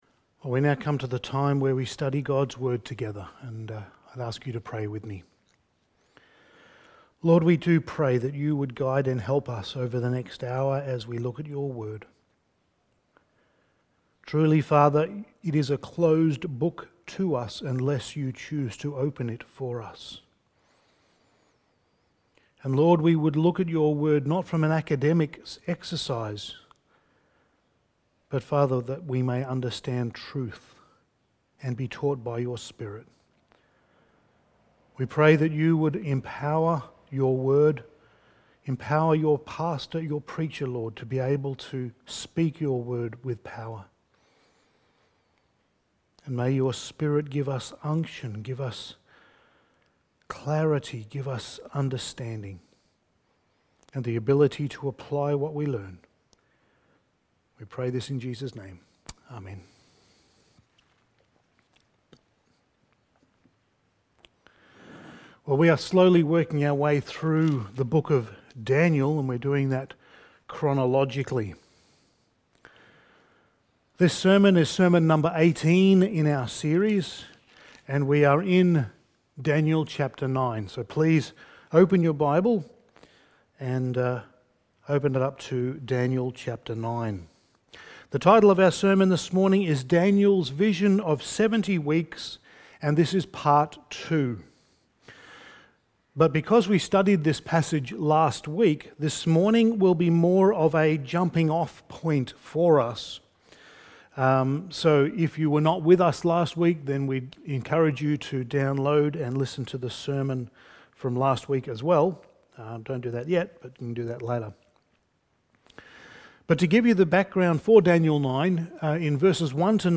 Passage: Daniel 9:20-27 Service Type: Sunday Morning